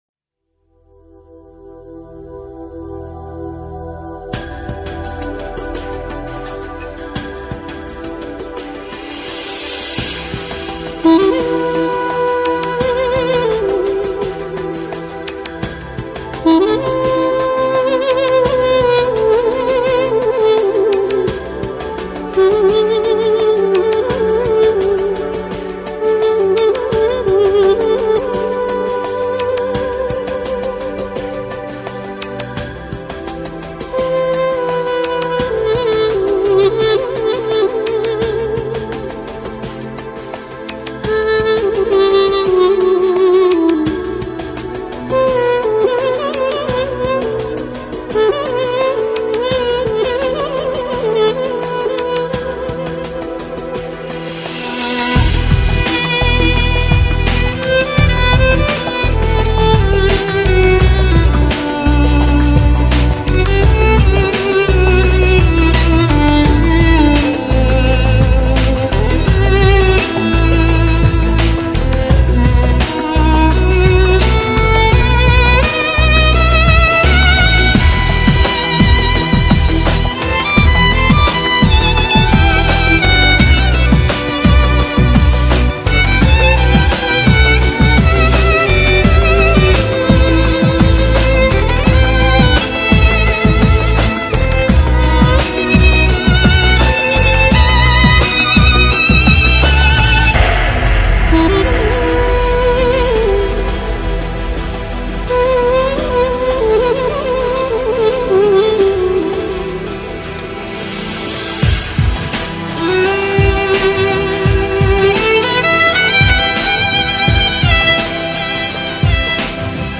Bozhestvenno__Skripka_i_dudukMP3_128K.mp3